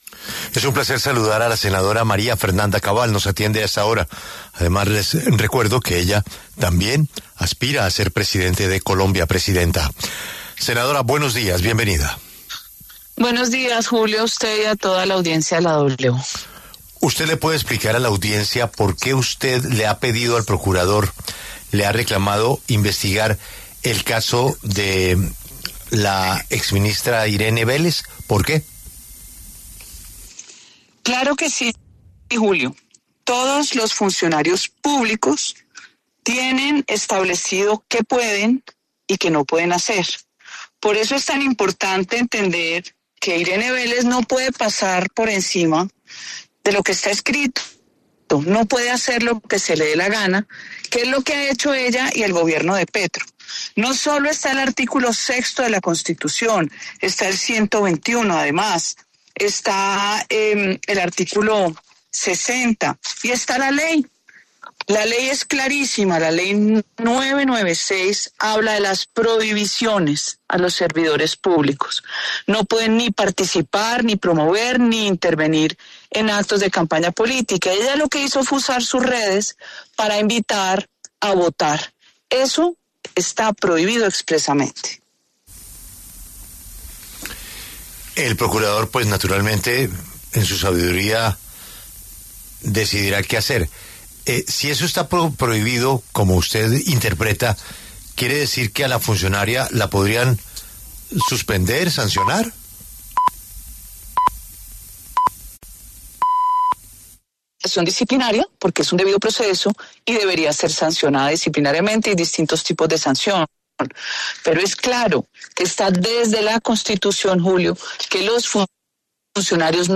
La senadora y precandidata presidencial, María Fernanda Cabal, habló en La W sobre su petición ante la Procuraduría y reflexionó sobre el panorama político de la derecha de cara al 2026.
La senadora y precandidata presidencial, María Fernanda Cabal, pasó por los micrófonos de La W para hablar sobre su petición a la Procuraduría de investigar a la ministra encargada de Ambiente, Irene Vélez, por una presunta participación en política.